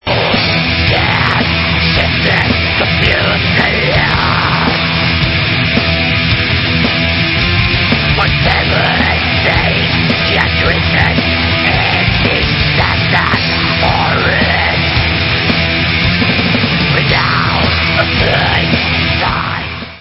+ 15 YEARS OF SATANIC BLACK METAL // 2008 ALBUM